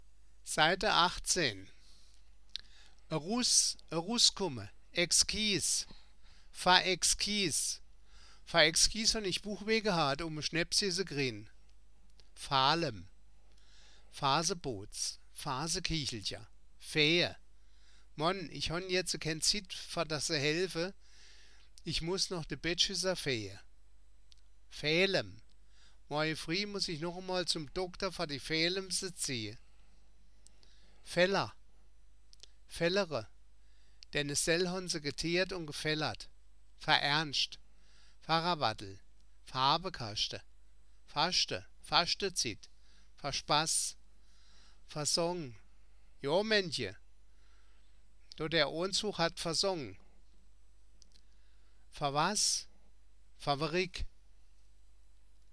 Das Wörterbuch der Ensheimer Mundart, Band I. Ensheim-Saar 1975